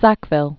(săkvĭl), Thomas First Earl of Dorset. 1536-1608.